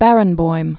(bărĭn-boim), Daniel Born 1942.